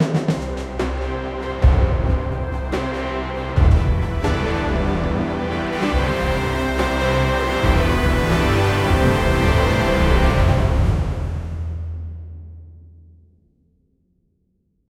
Thunderous orchestral score with deep, resonating drums.
thunderous-orchestral-sco-t3vdwwwa.wav